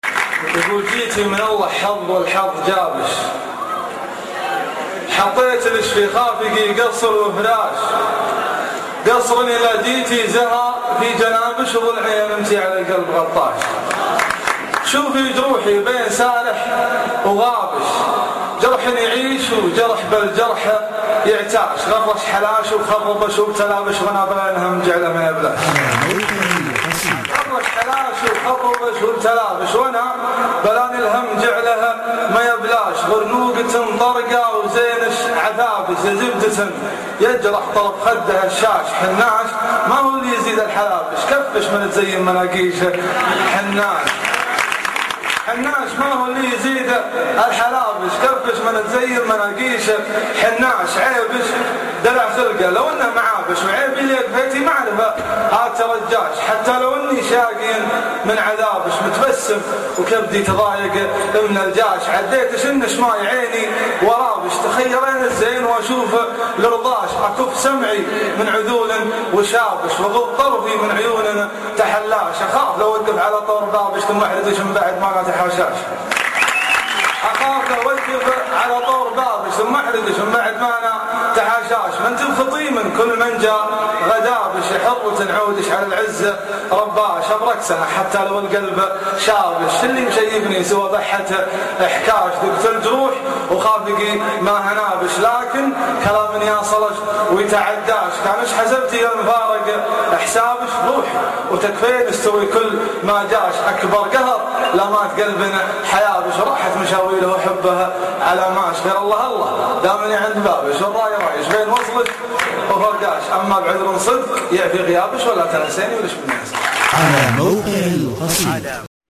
الحظ جابش ( اصبوحة جامعة الكويت )   05 ابريل 2012